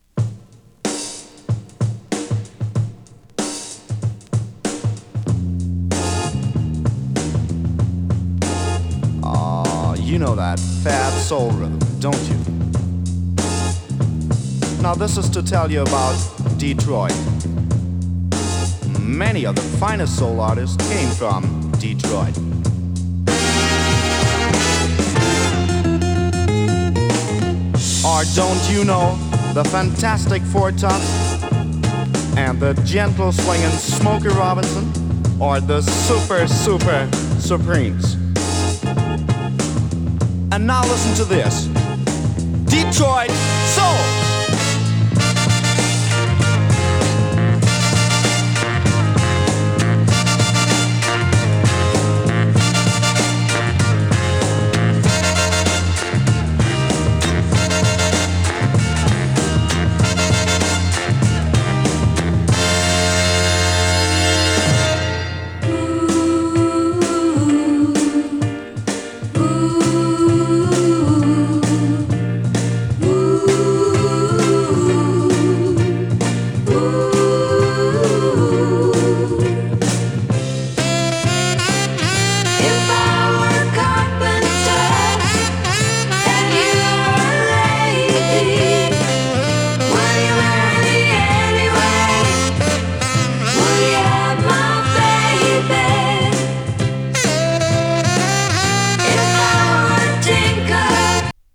シャープな演奏に華やかな女性コーラスが花を添えたダンス・アルバムです！